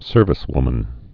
(sûrvĭs-wmən)